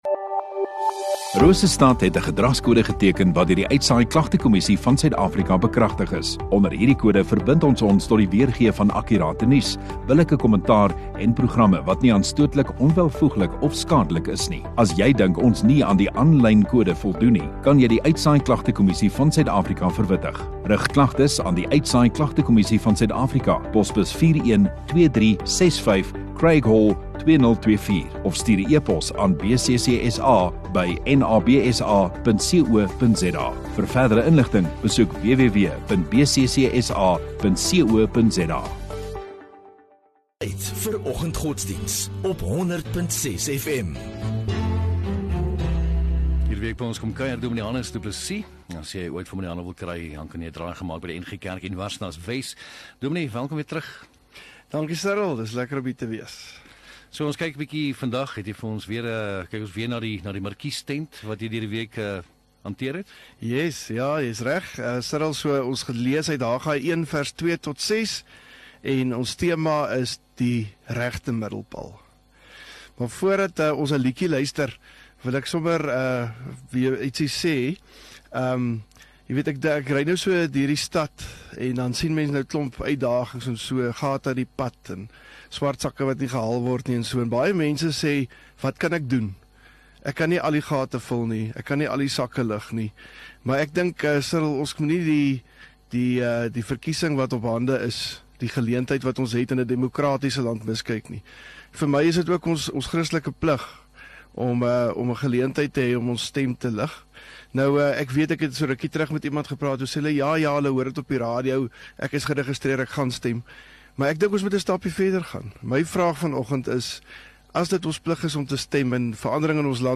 15 Mar Vrydag Oggenddiens